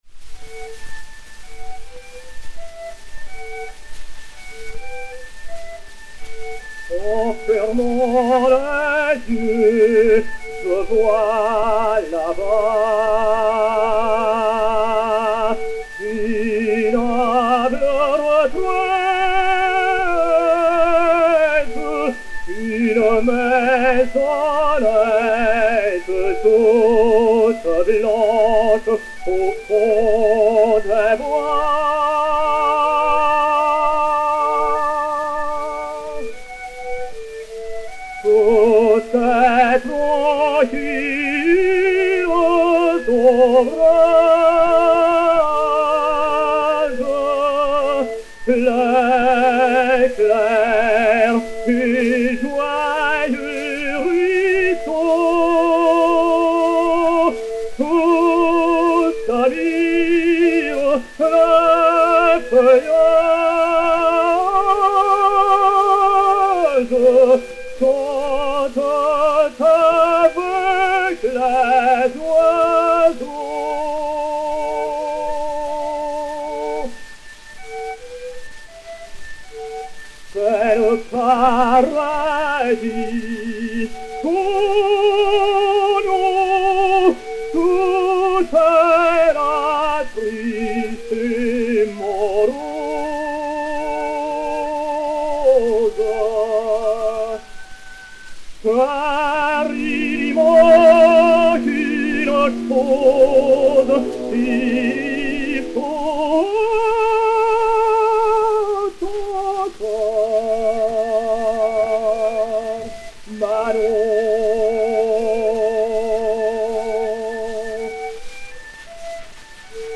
ténor français
Léon Beyle (Des Grieux) et Orchestre
Disque Pour Gramophone 232.414, mat. 6107o, enr. à Paris en 1906